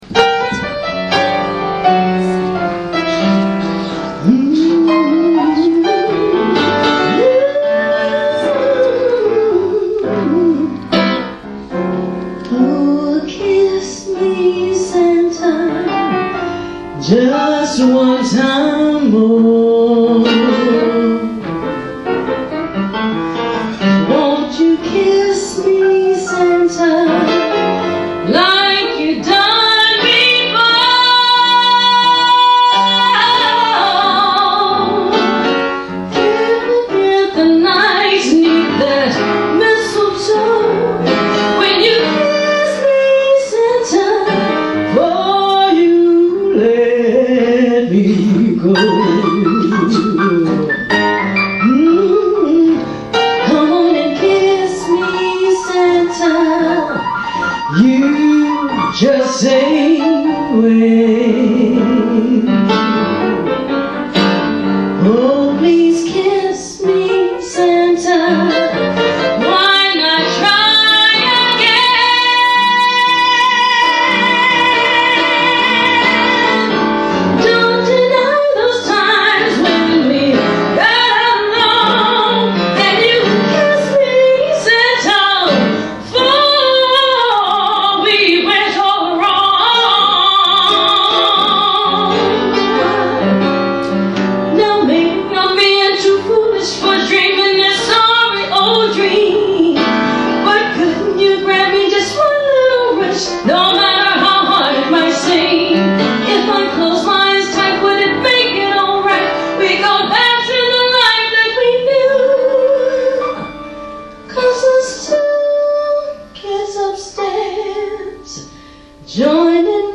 Cabaret Songs
Piano